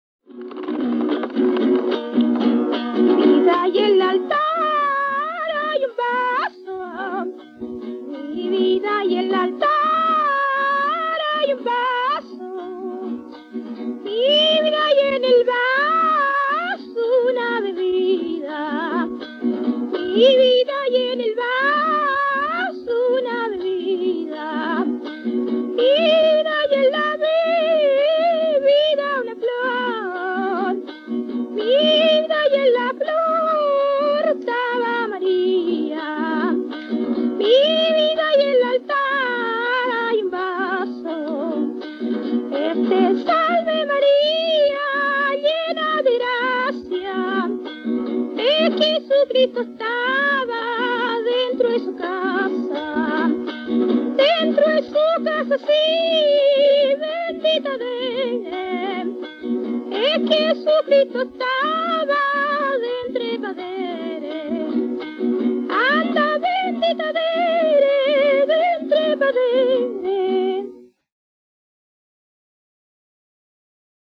quien se acompaña con una guitarra afinada por la orilla.
Música tradicional
Folklore
Cueca